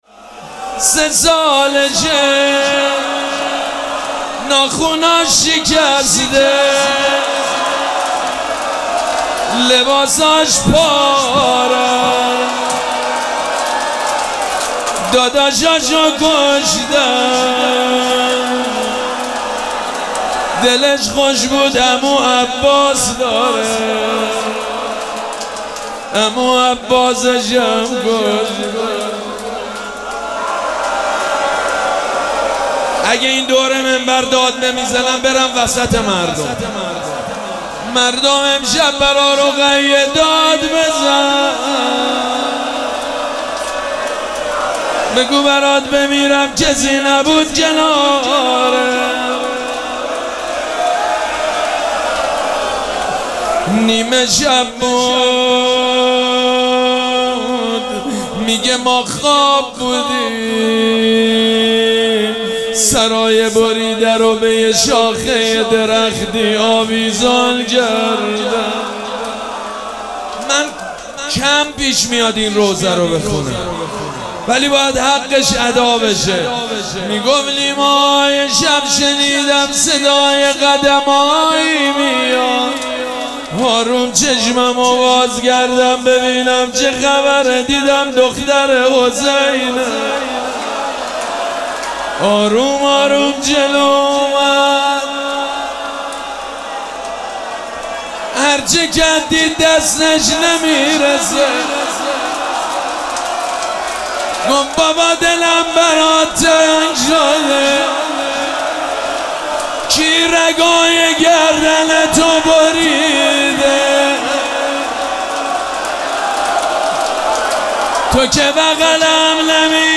مراسم عزاداری شب سوم محرم الحرام ۱۴۴۷
روضه